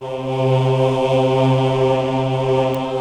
Index of /90_sSampleCDs/AKAI S6000 CD-ROM - Volume 1/VOCAL_ORGAN/BIG_CHOIR
CHOIR-3   -S.WAV